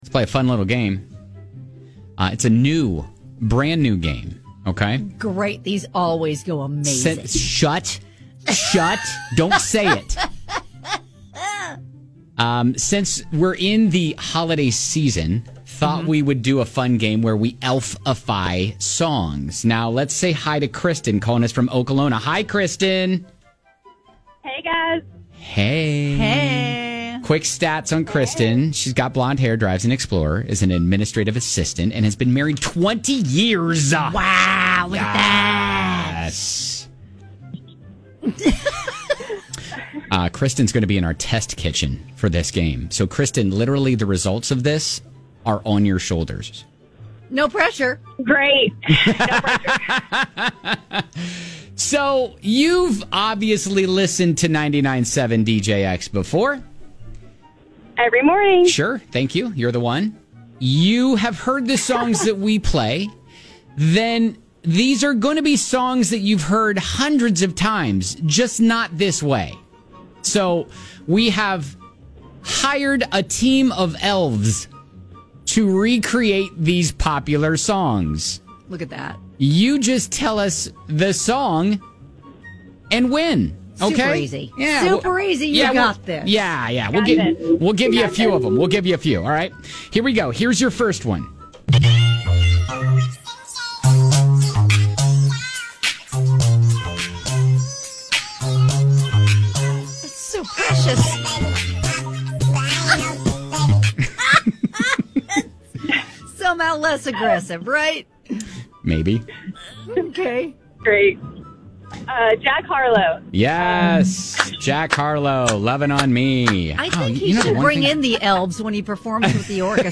We've hired elves to recreate these popular songs on DJX. How many can you get right?